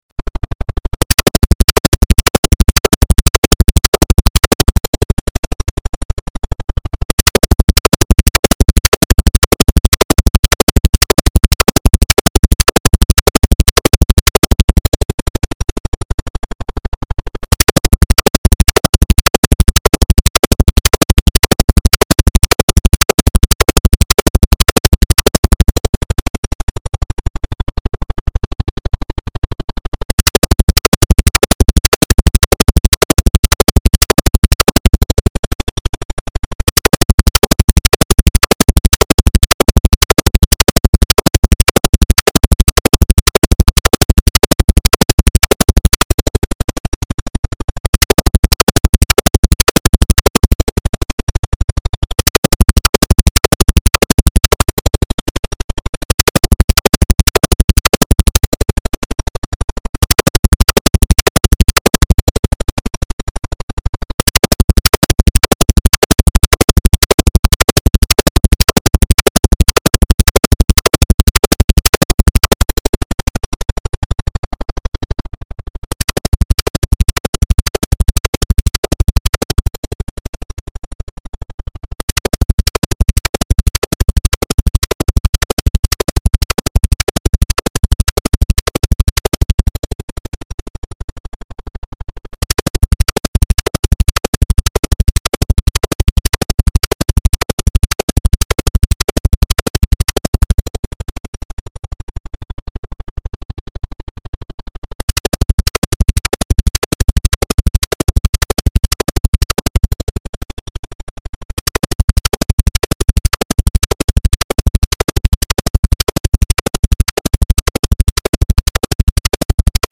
Звуки удаления воды
Подборка включает различные частоты и тональности, оптимальные для очистки наушников, смартфонов и ноутбуков.